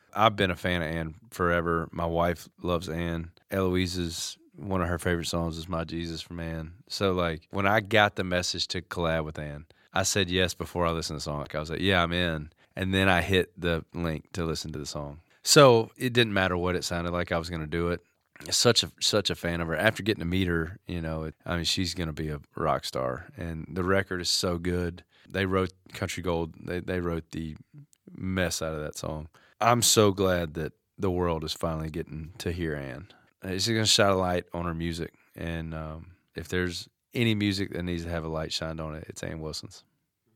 Jordan Davis says he didn't even need to hear "Country Gold" before he said yes to collaborating with Anne Wilson.